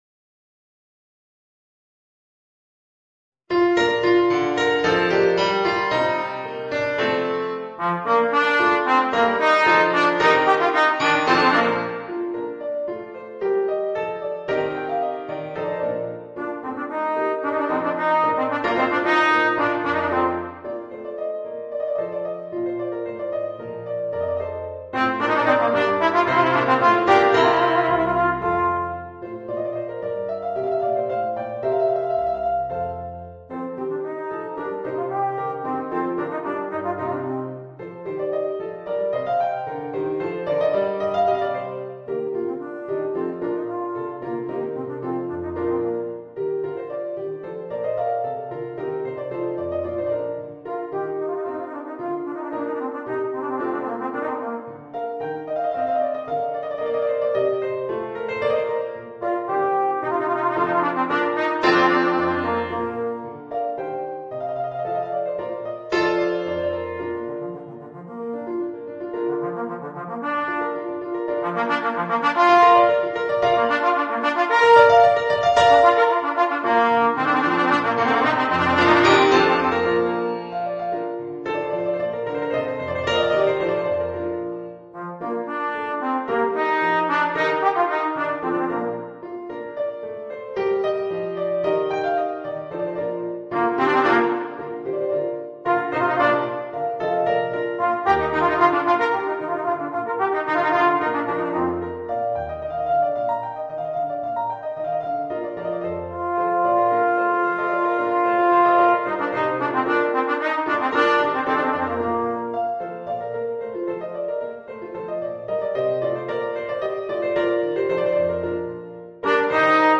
Voicing: Trombone and Organ